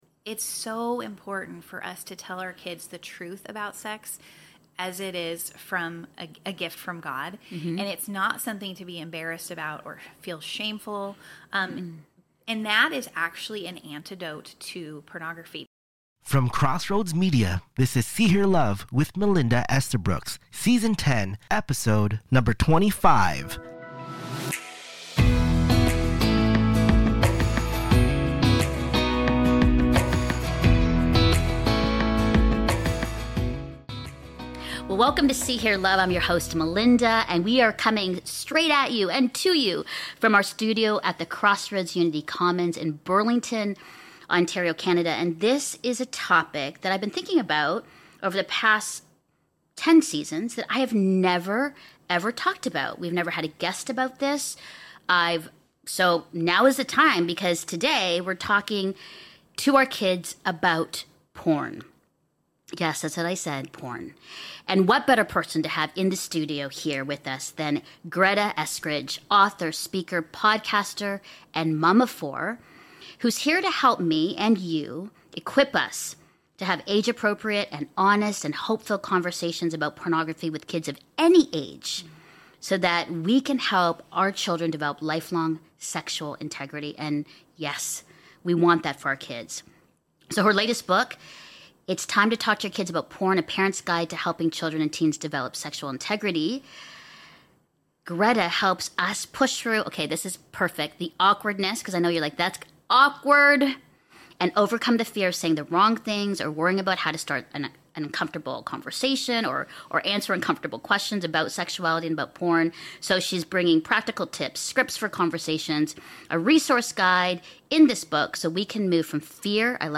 Season 10 SOS Podcast Episode 3: One on One Interview